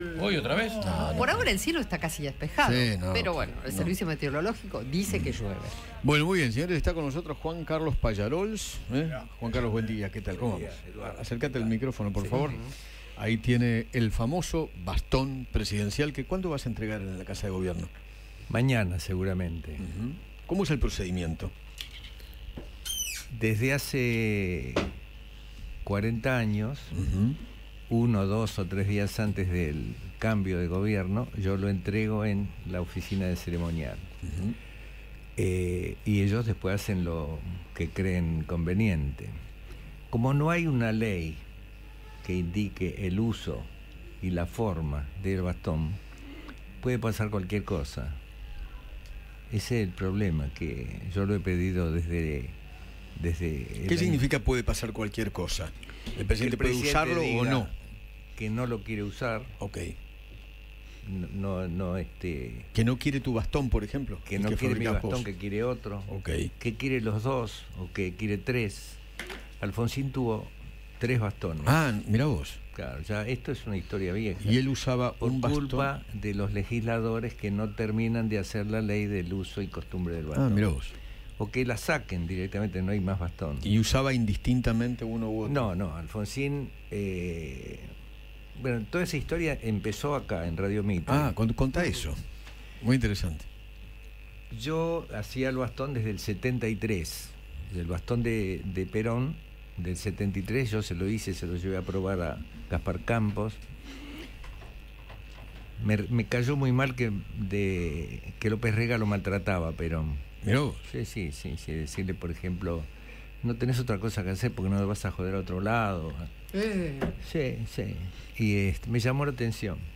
El platero Juan Carlos Pallarols visitó a Eduardo Feinmann en los estudios de Radio Mitre y mostró el bastón presidencial que podría usar Javier Milei en su asunción.